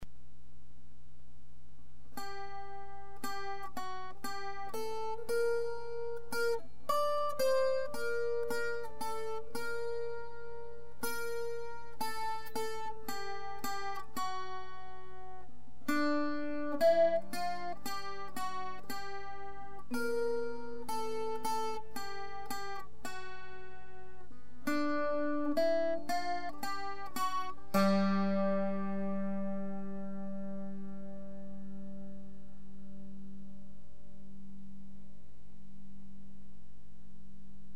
Chansons populaires françaises